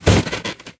anonDeskSlam.ogg